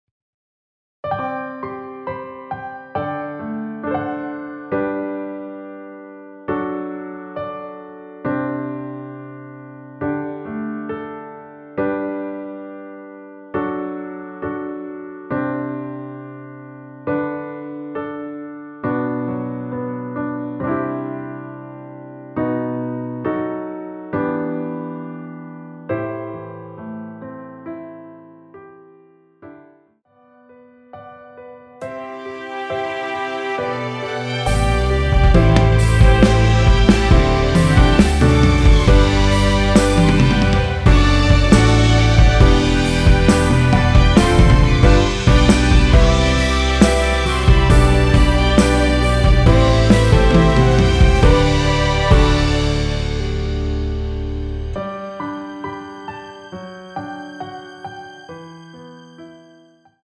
원키에서(+4)올린 MR입니다.
◈ 곡명 옆 (-1)은 반음 내림, (+1)은 반음 올림 입니다.
앞부분30초, 뒷부분30초씩 편집해서 올려 드리고 있습니다.